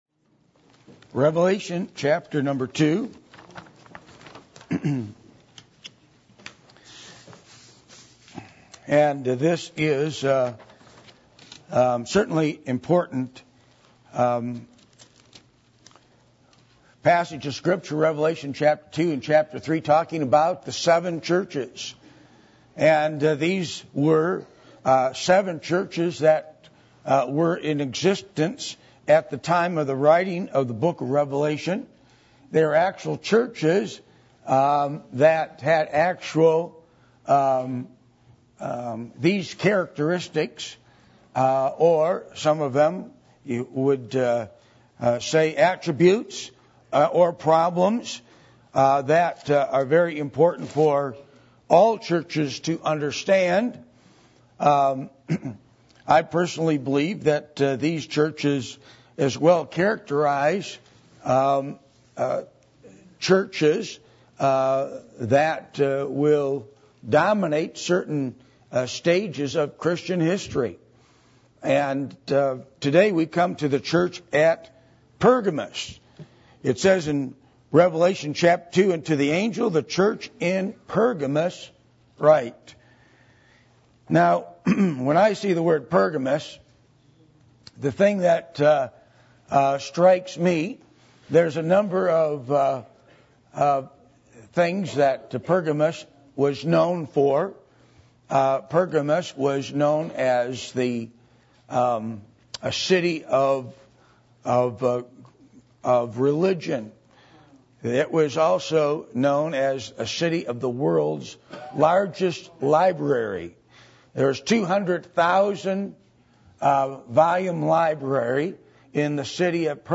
Passage: Revelation 2:12-17 Service Type: Sunday Morning